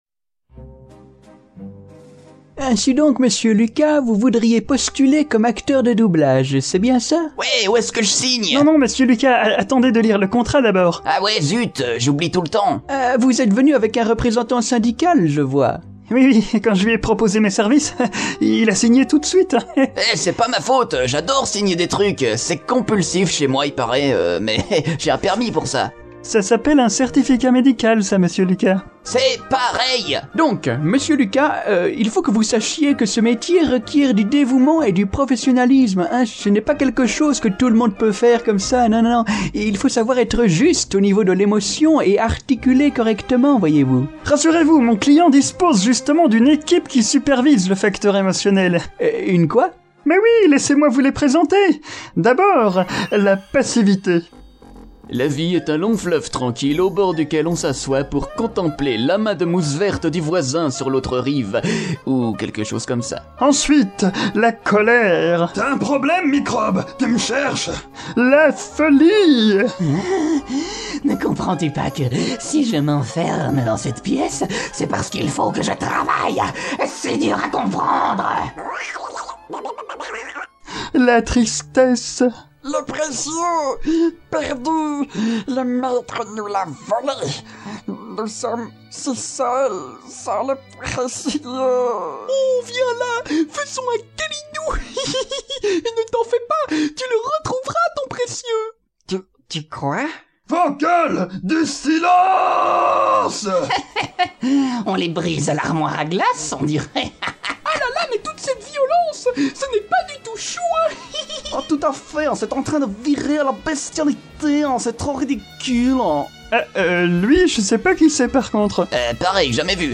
Démo vocale